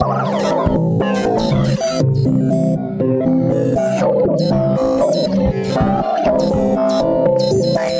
描述：工业电子循环，合成无人机，哔哔声气氛
Tag: 哔哔声 无人驾驶飞机 合成的 大气 电子 工业